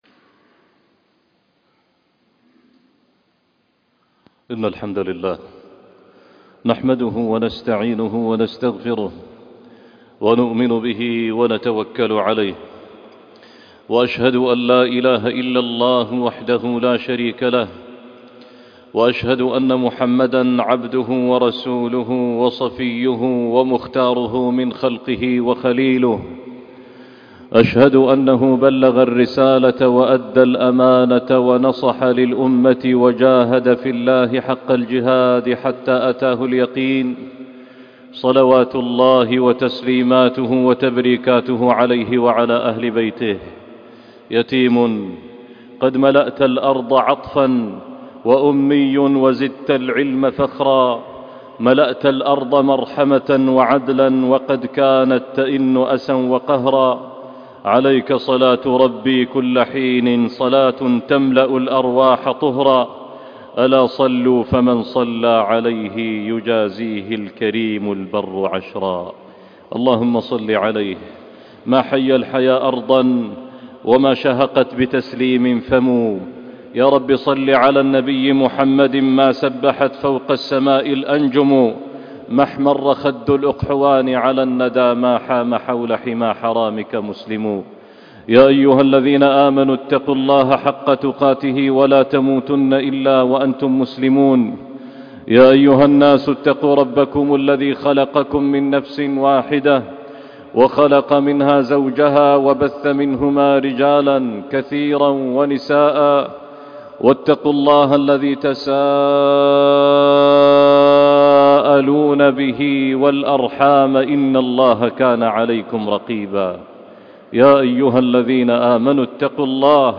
خطبة وصلاة الجمعة